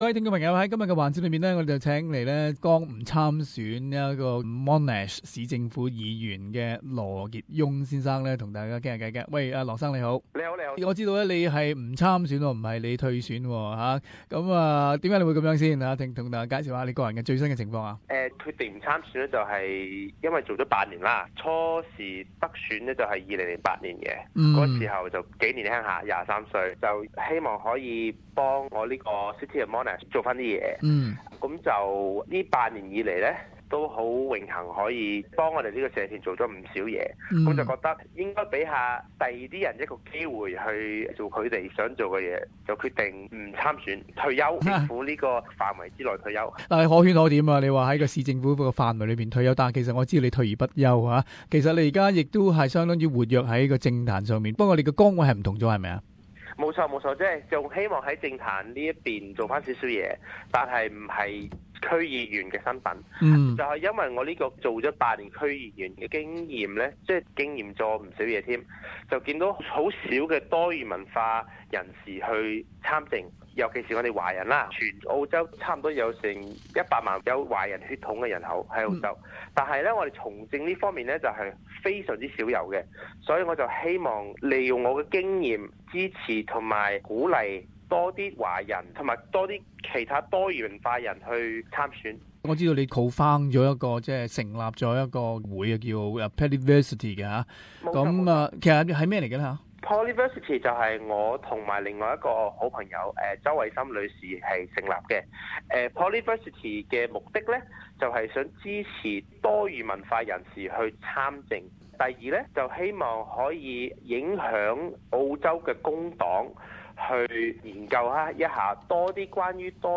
【社团专访】